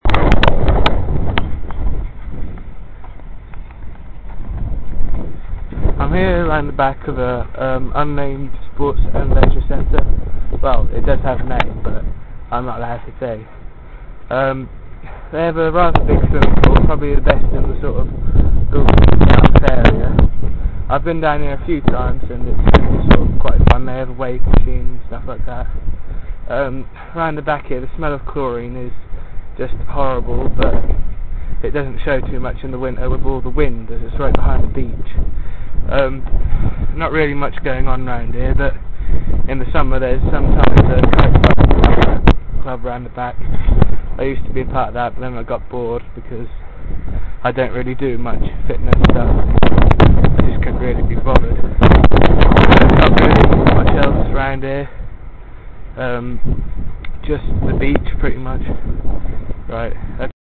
INSTRUCTIONS: Record an audio file of yourself in a strange place you like.